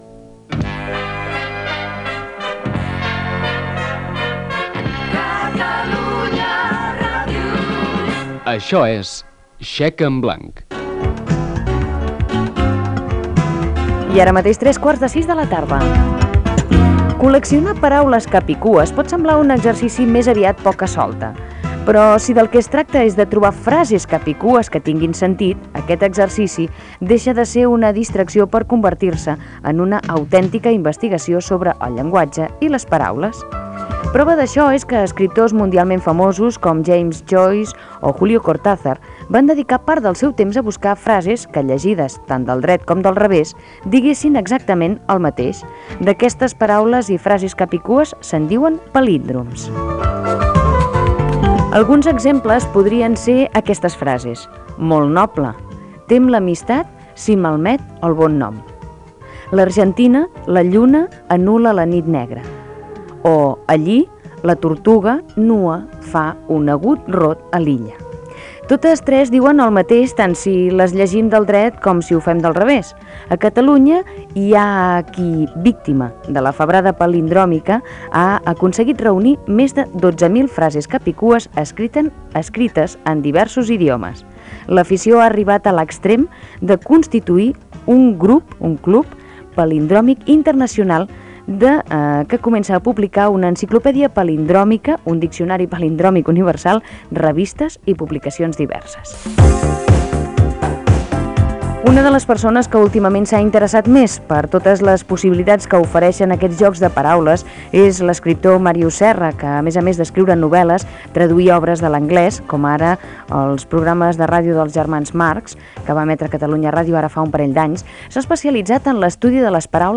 Indicatius de l'emissora i del programa, les frases cap-i-cua amb una entrevista a l'escriptor Màrius Serra que ha publicat "Manual d'enigmística"
Entreteniment